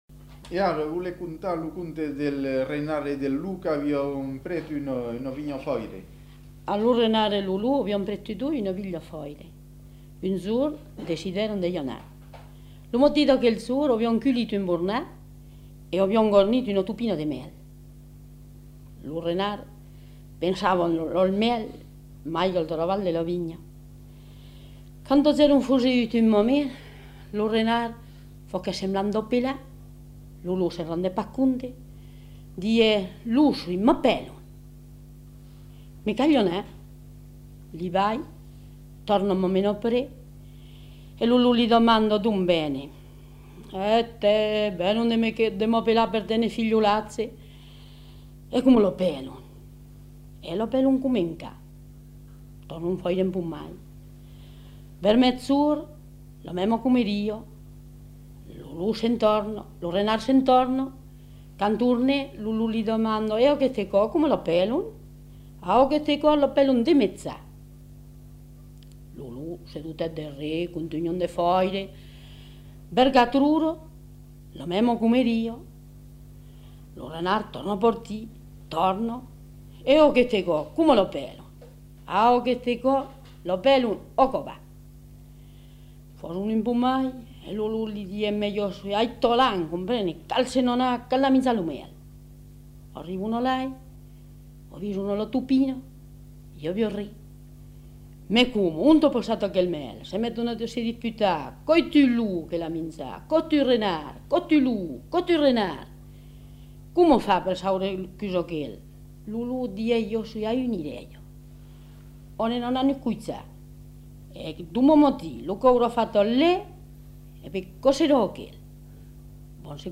Genre : conte-légende-récit
Effectif : 1
Type de voix : voix de femme
Production du son : parlé